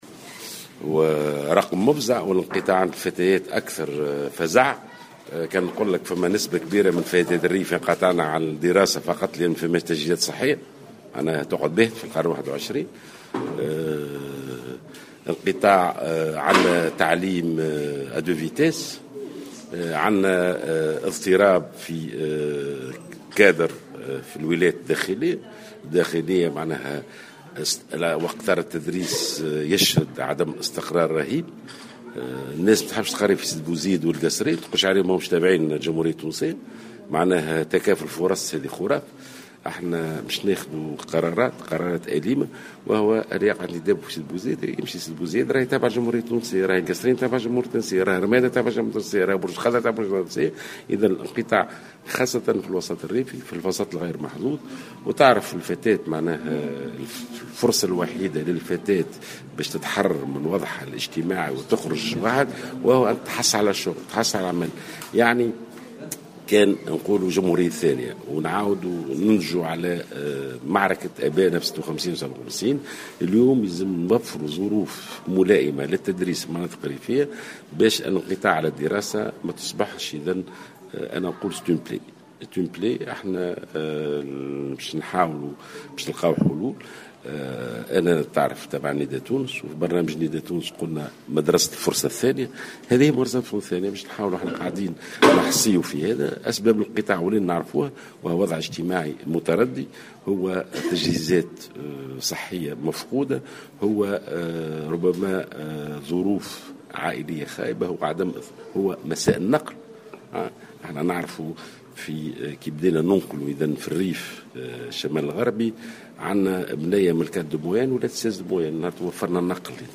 وبين جلول، في تصريح لمراسل الجوهرة أف أم أن أسباب هذا الانقطاع تتعلق خصوصا بالظروف الاجتماعية والتجهيزات الصحية المفقودة وصعوبة التنقل إلى المؤسسات التعليمية الريفية.